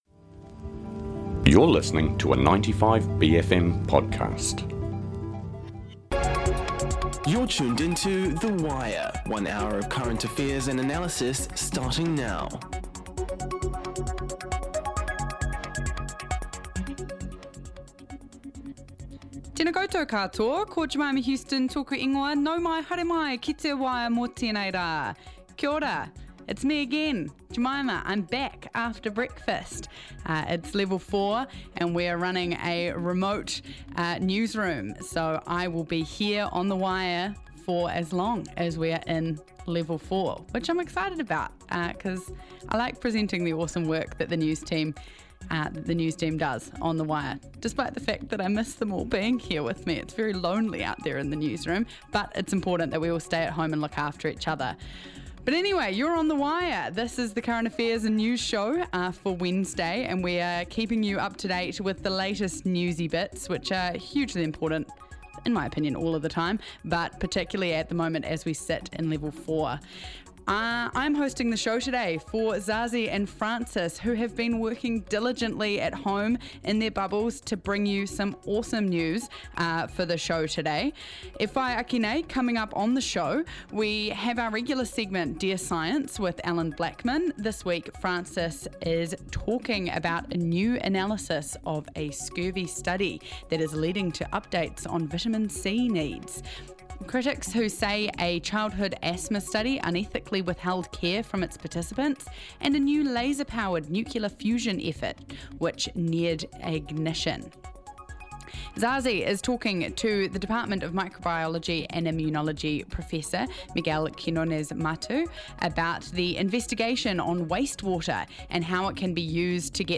The Wire is 95bFM's long-running daily bastion of news, current affairs and views through the bFM lens.